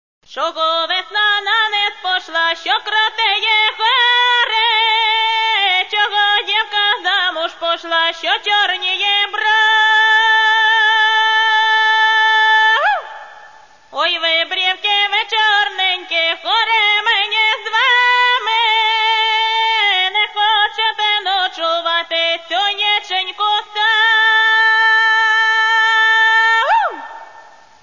Catalogue -> Folk -> Singers